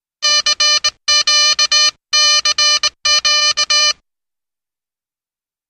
Morse Code Beeps.